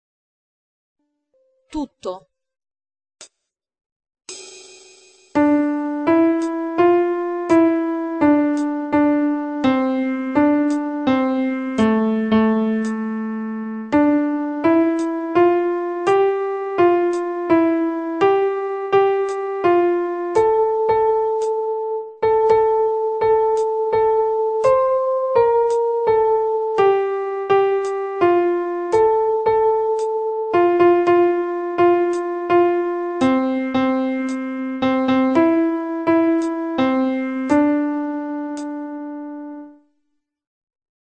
Questo brano in Re minore usa molte terzine in due tempi.